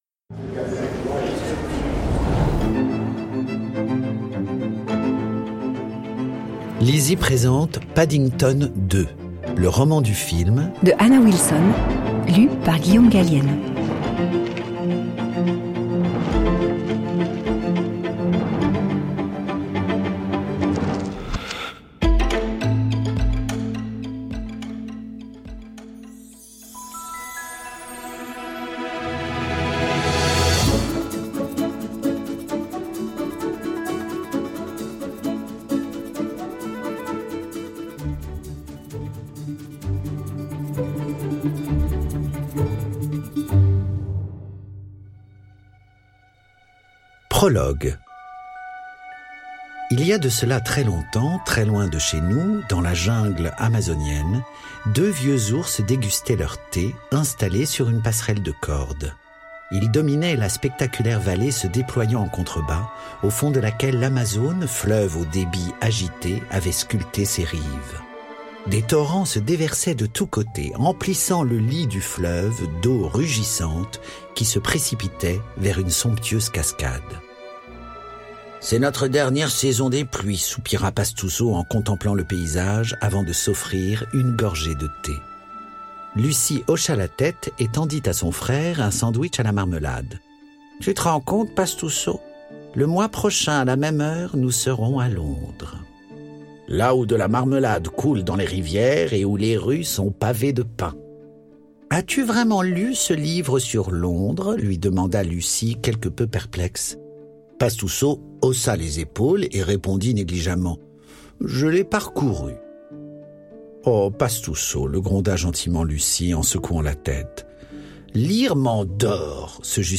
je découvre un extrait - Paddington 2 : le roman du film de Anna Wilson
Lu par Guillaume Gallienne, la voix française de Paddington Durée : 03H43 × Guide des formats Les livres numériques peuvent être téléchargés depuis l'ebookstore Numilog ou directement depuis une tablette ou smartphone.